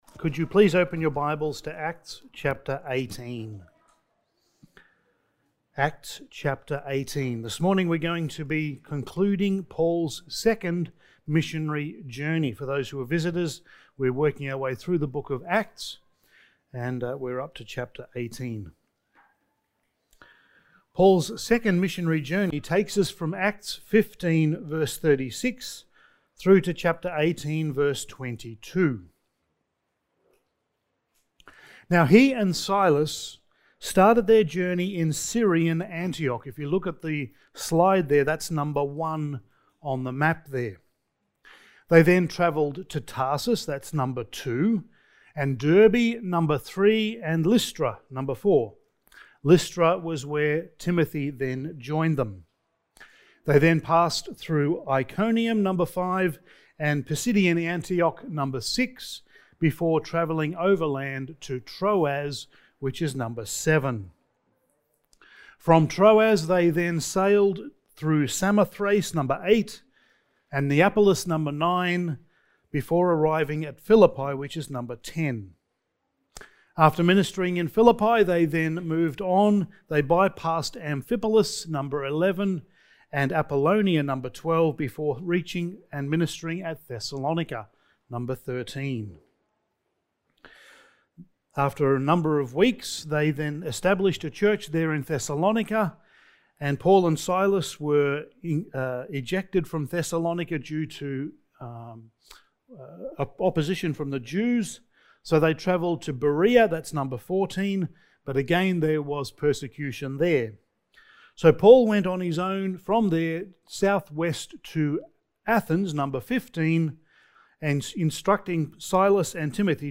Passage: Acts 18:23 Service Type: Sunday Morning